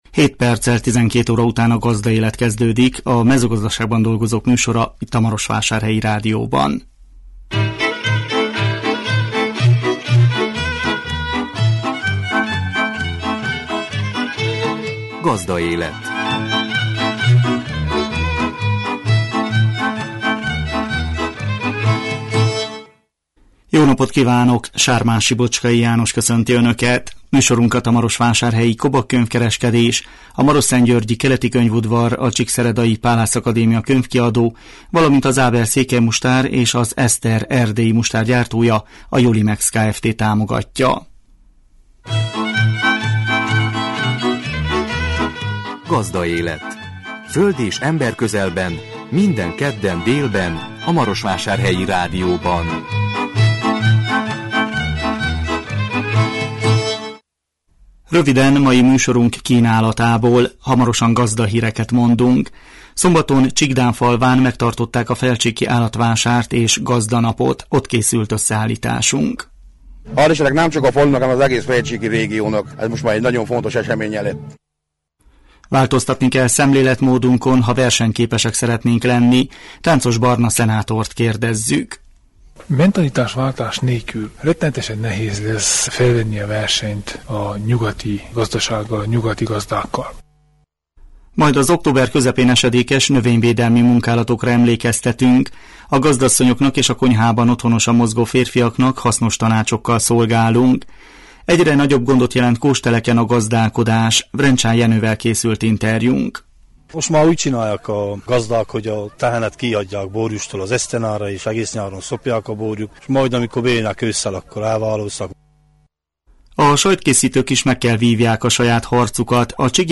A 2016 október 11-én jelentkező műsor tartalma: Gazdahírek, Szombaton Csíkdánfalván megtartották a felcsíki állatvásárt és gazdanapot. Ott készült összeállításunk.